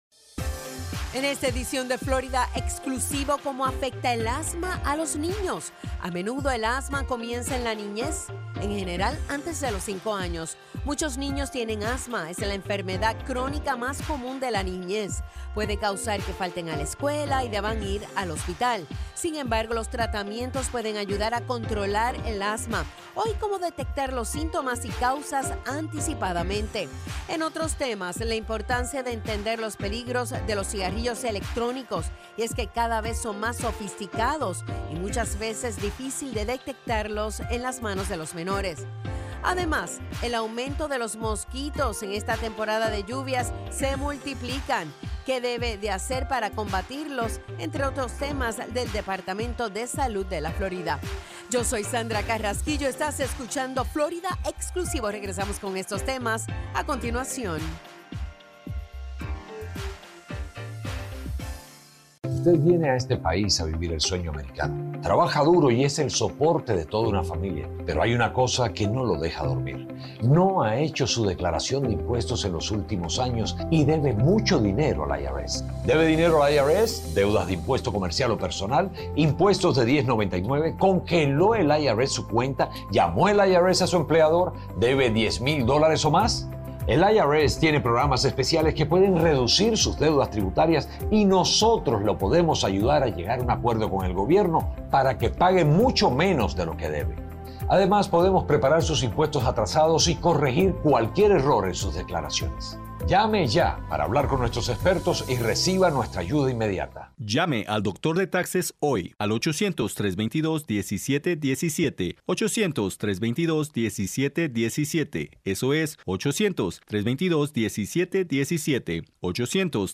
FNN's Florida Exclusivo is a weekly, one-hour news and public affairs program that focuses on news and issues of the Latino community.